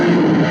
File:Styracosaurus thing roar.ogg
Styracosaurus_thing_roar.ogg